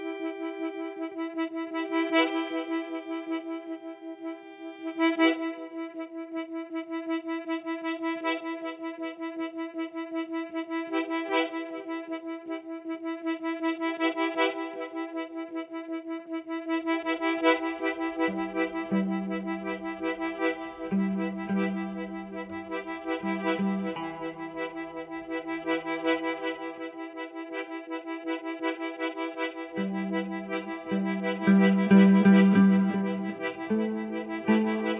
Жанр: Русская поп-музыка / Русский рок / Фолк-рок / Русские